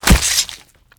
tinyblade.ogg